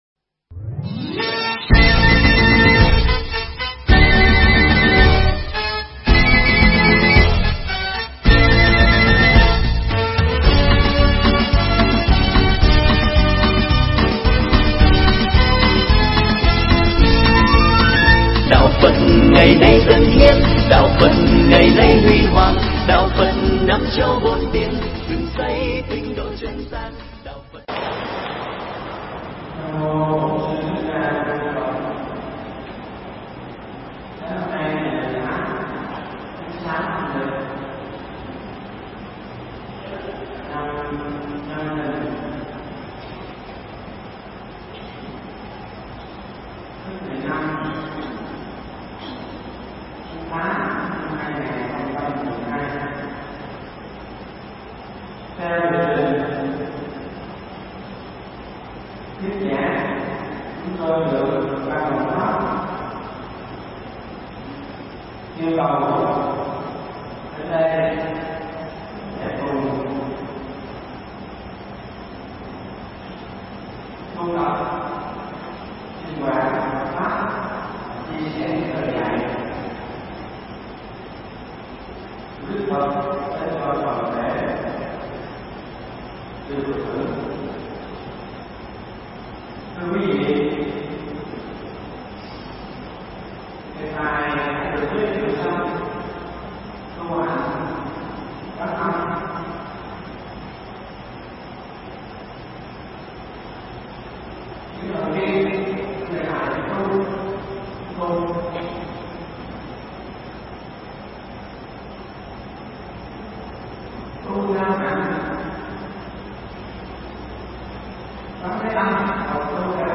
Nghe Mp3 thuyết pháp Tu Hạnh Quan Âm
Mp3 pháp thoại Tu Hạnh Quan Âm